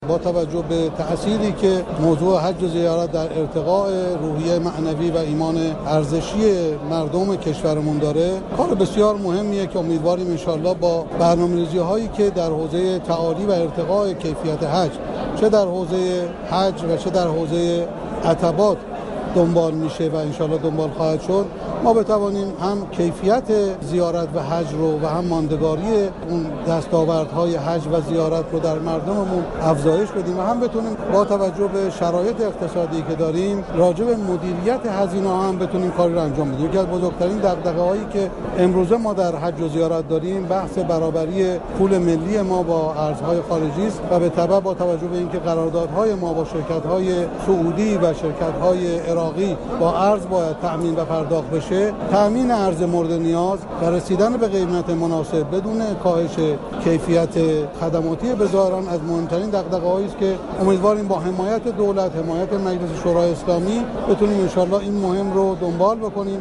سید عباس صالحی وزیر فرهنگ و ارشاد اسلامی در آیین معارفه علیرضا رشیدیان رئیس جدید سازمان حج و زیارت ضمن تشكر از زحمات حمید محمدی رئیس پیشین این سازمان گفت:امیدواریم حضور رئیس جدید موجب رضایتمندی بیشتر حجاج شود .